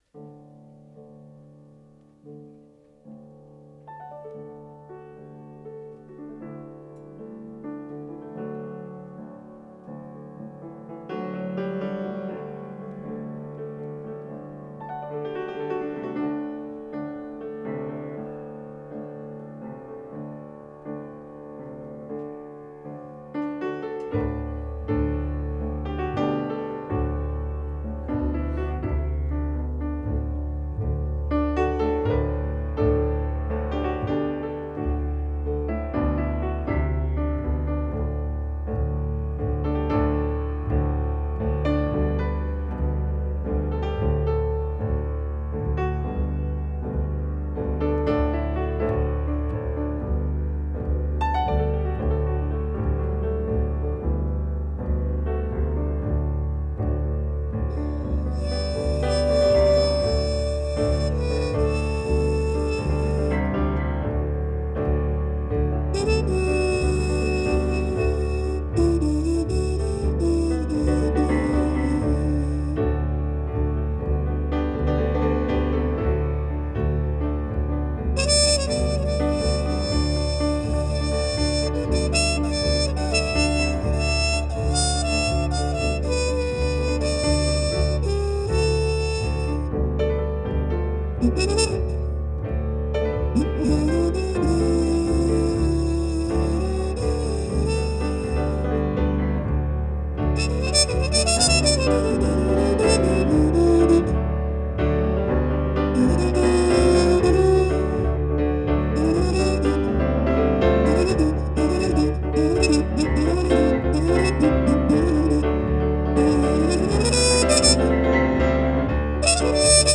ganz eigenen, sphärischen und dennoch kraftvollen Sound